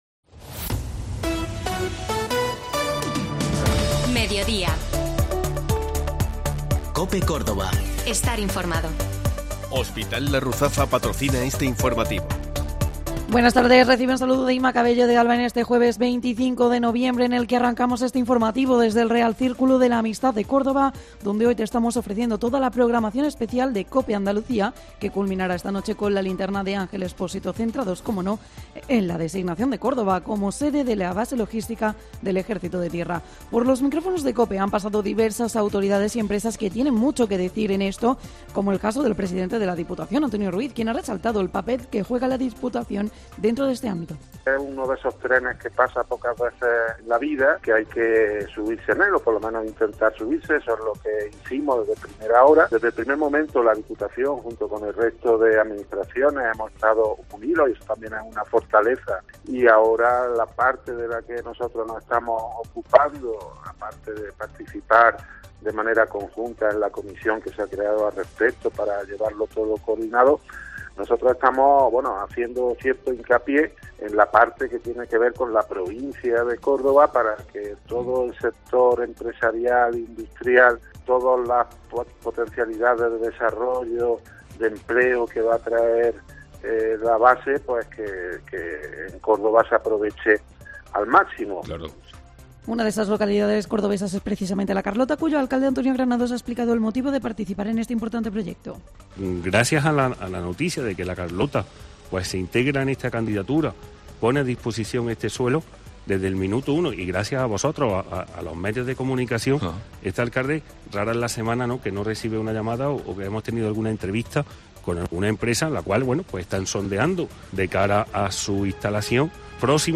La actualidad de este jueves ha estado en el Real Círculo de la Amistad desde donte COPE está ofreciendo una programación especial centrada en la repercusión de la base logística del Ejército.
Te contamos las últimas noticias de Córdoba y provincia con los reportajes que más te interesan y las mejores entrevistas.